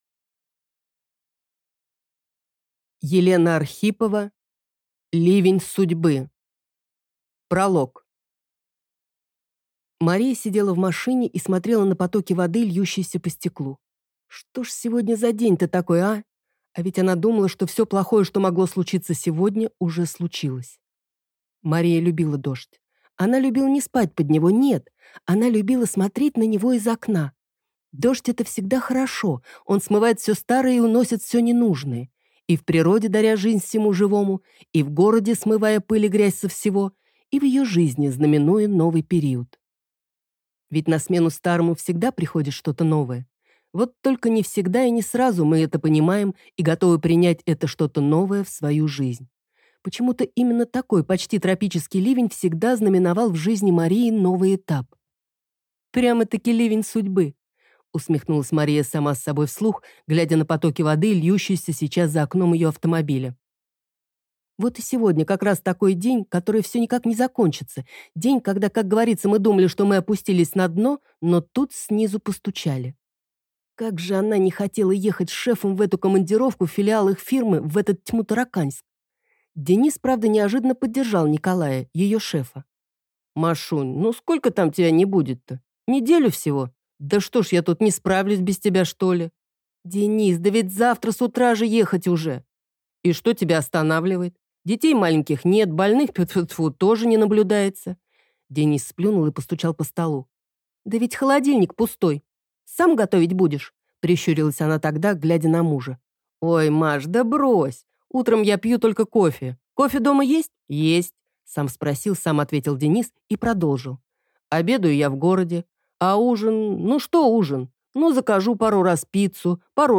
Аудиокнига Ливень судьбы | Библиотека аудиокниг